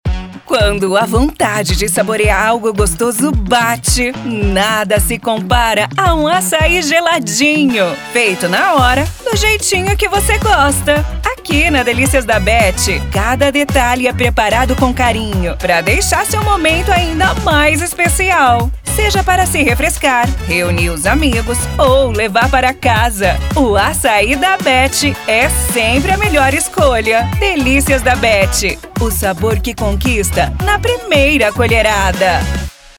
alegre:
Animada